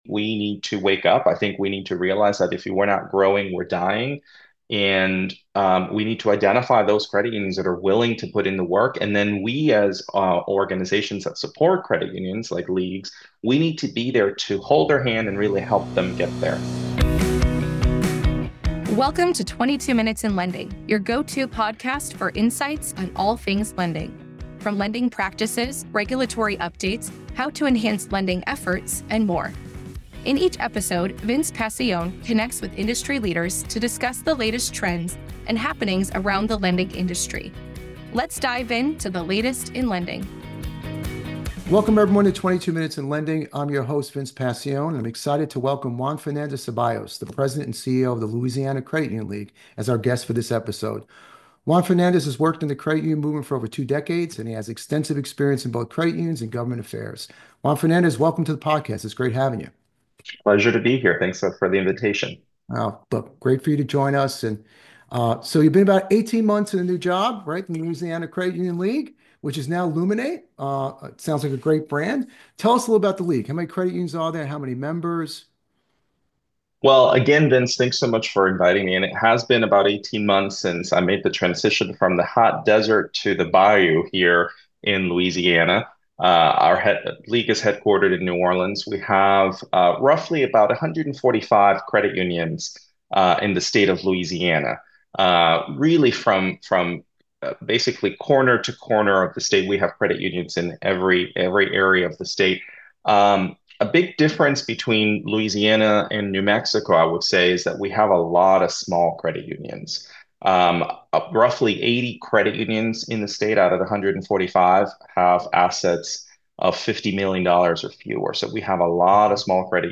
This conversation is a must-listen.